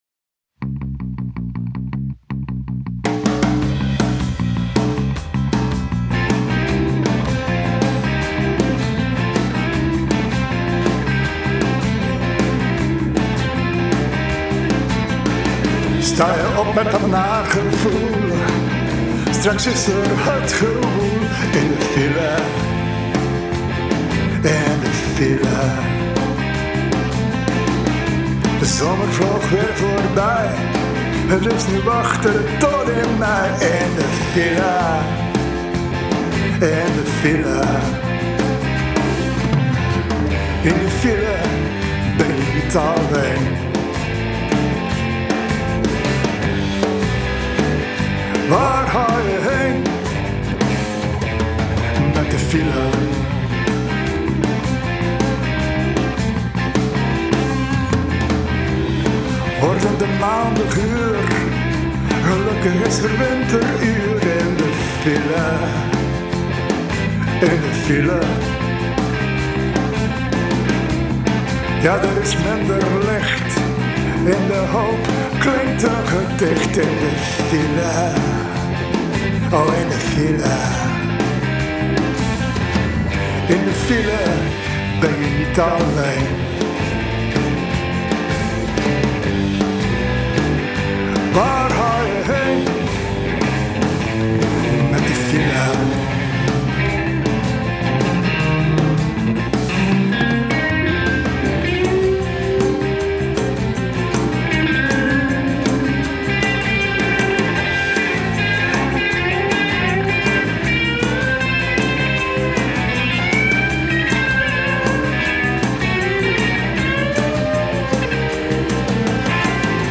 Een fijn rock-nummer…
Eenvoudige tekst, gemakkelijk mee te zingen!